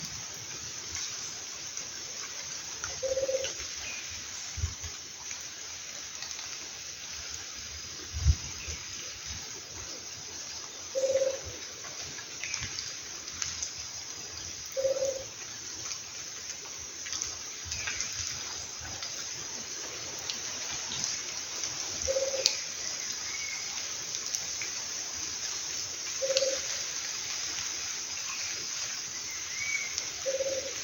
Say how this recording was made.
Country: Brazil Detailed location: Parque estadual do turvo. camino a saltos de Yucuma Condition: Wild Certainty: Observed, Recorded vocal